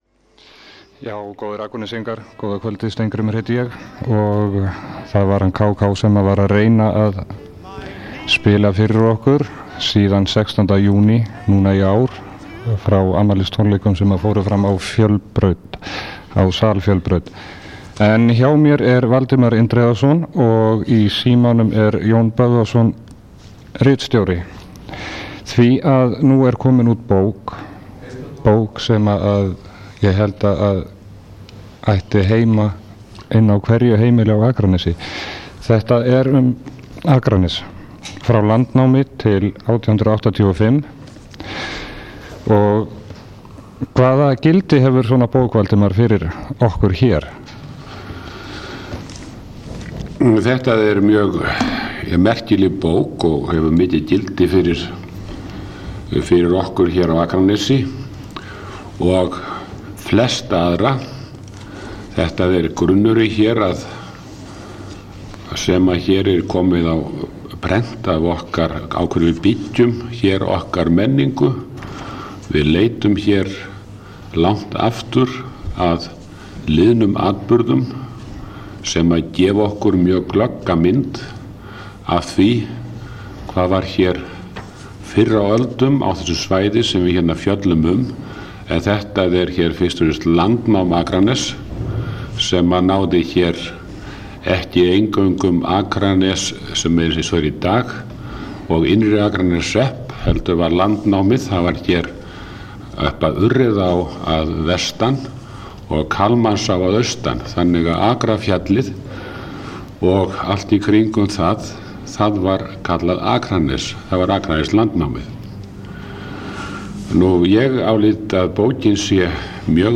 Tónlist og viðtöl úr bæjarlífinu.